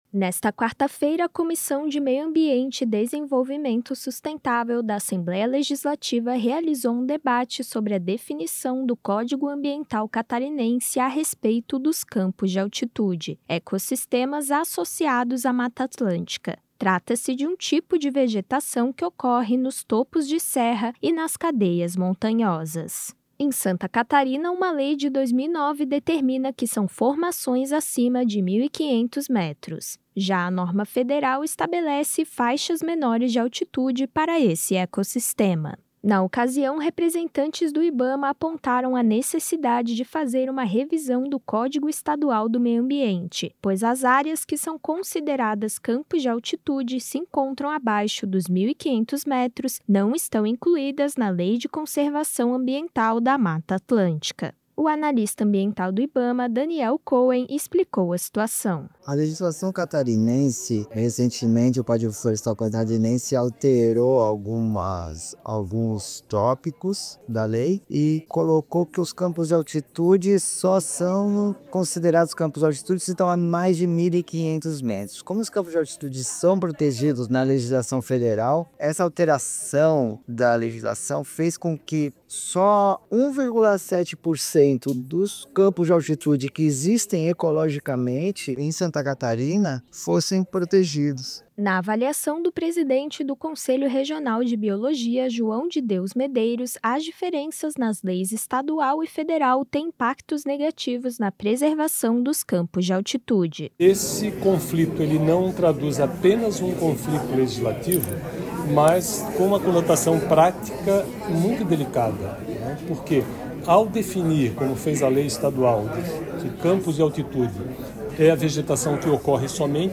Entrevistas com: